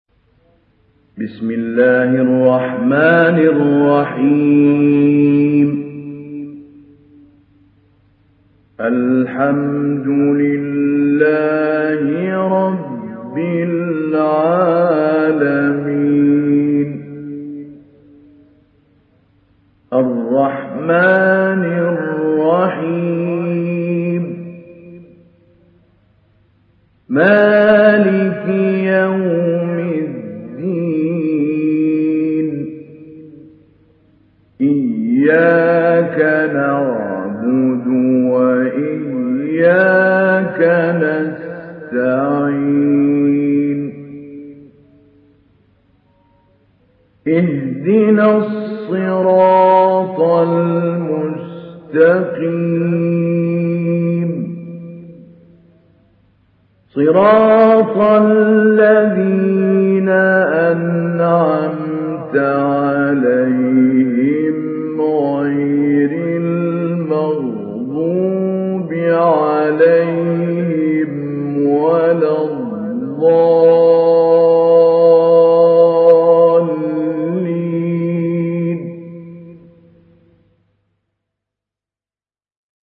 সূরা আল-ফাতিহা ডাউনলোড mp3 Mahmoud Ali Albanna Mujawwad উপন্যাস Hafs থেকে Asim, ডাউনলোড করুন এবং কুরআন শুনুন mp3 সম্পূর্ণ সরাসরি লিঙ্ক
ডাউনলোড সূরা আল-ফাতিহা Mahmoud Ali Albanna Mujawwad